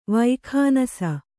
♪ vaikhānasa